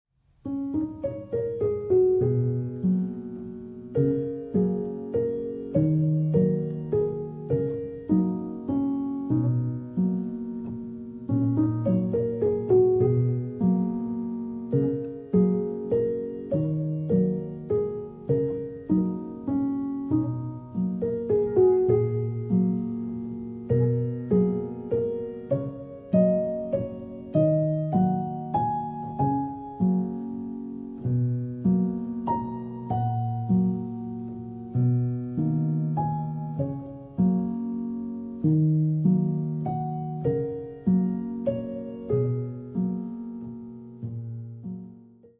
延々と続く桜並木を歩む夢を見るようなアルバムです。